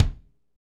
Index of /90_sSampleCDs/Northstar - Drumscapes Roland/DRM_Funk/KIK_Funk Kicks x
KIK FNK K08L.wav